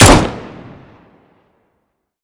Index of /server/sound/weapons/dod_m1911
usp_unsilenced_03.wav